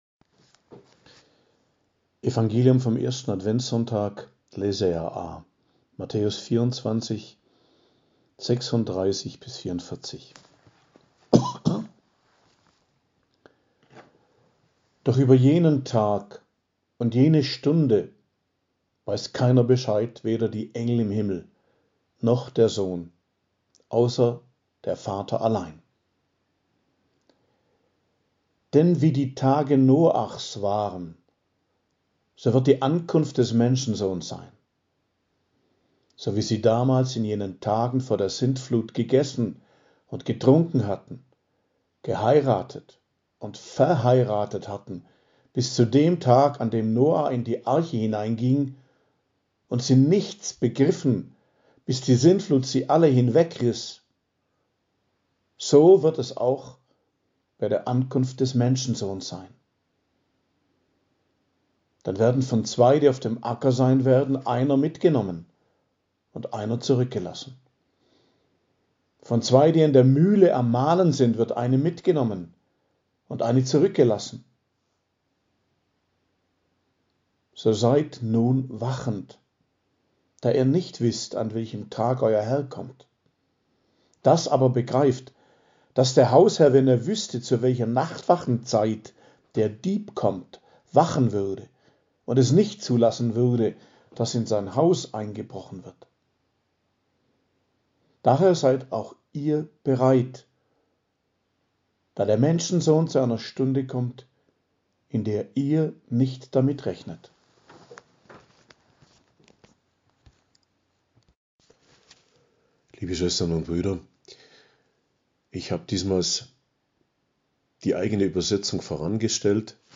Predigt zum 1. Adventssonntag, 27.11.2022 ~ Geistliches Zentrum Kloster Heiligkreuztal Podcast